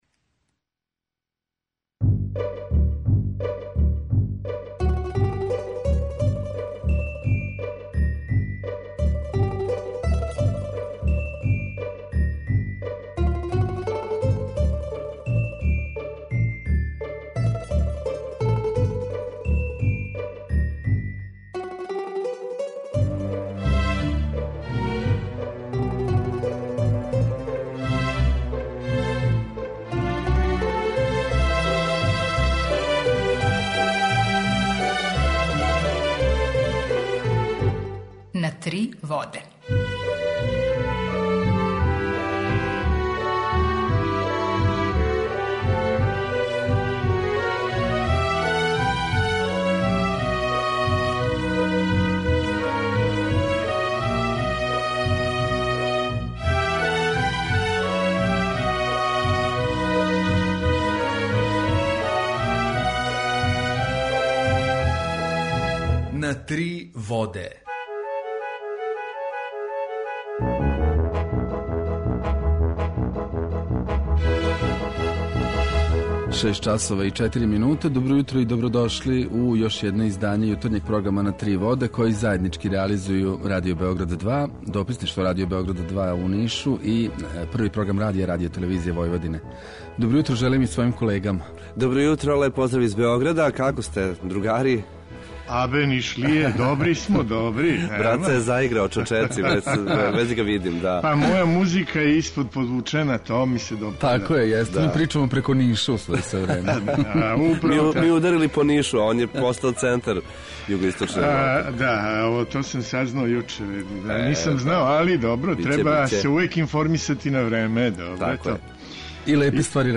Jутарњи програм заједнички реализују Радио Београд 2, Радио Нови Сад и дописништво Радио Београда из Ниша
У два сата, ту је и добра музика, другачија у односу на остале радио-станице.